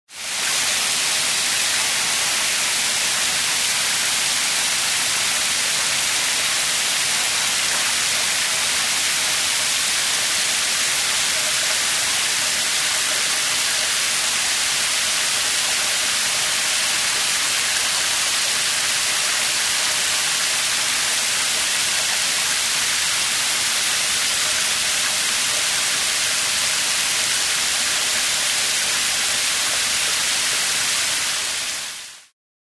На этой странице собраны разнообразные звуки душа: от мягкого потока воды до интенсивного массажного режима.
Шум воды в душе — 1 вариант